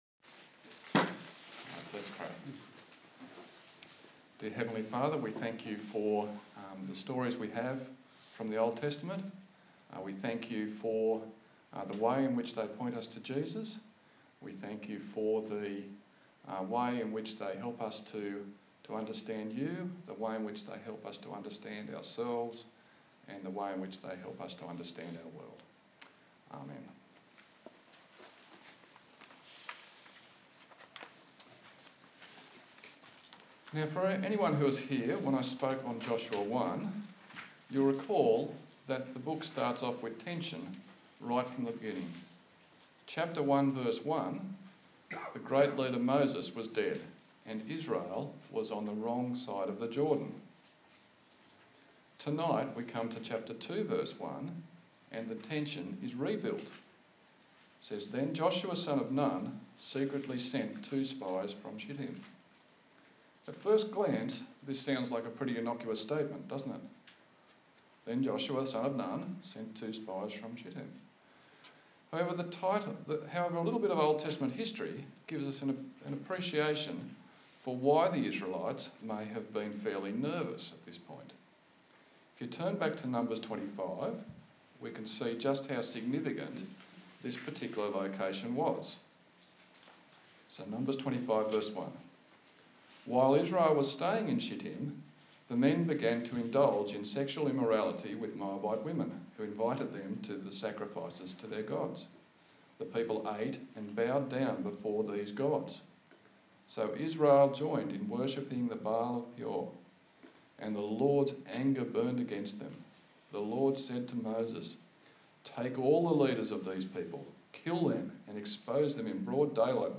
A sermon on the book of Joshua
Service Type: TPC@5